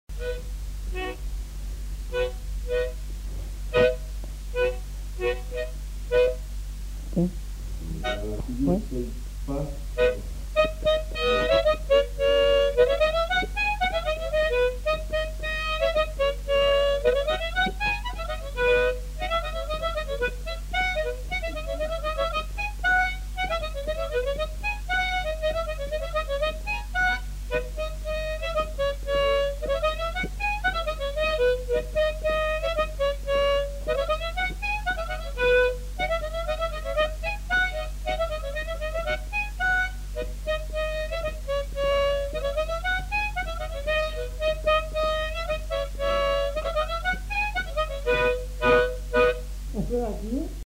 Aire culturelle : Haut-Agenais
Genre : morceau instrumental
Instrument de musique : harmonica
Danse : congo